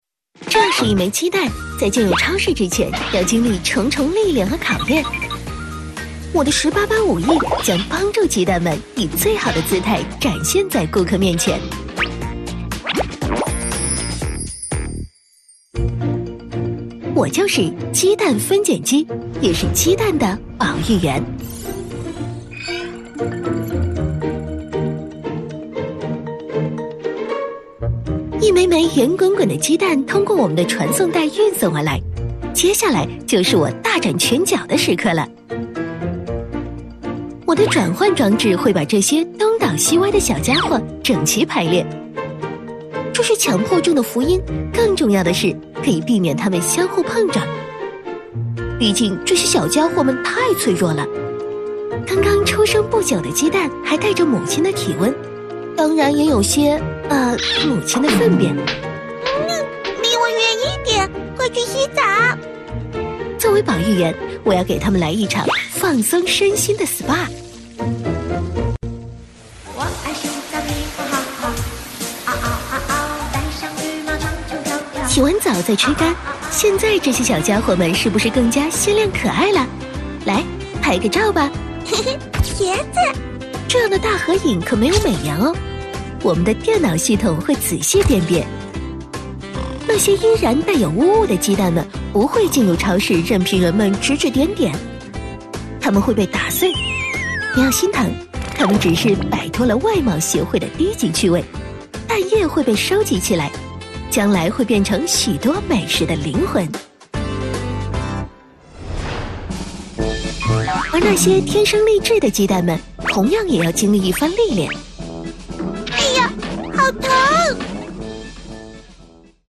女97-MG动画 - 轻松活泼-CCTV17《村里来了钢铁侠》
女97全能配音 v97
女97-MG动画----轻松活泼-CCTV17-村里来了钢铁侠-.mp3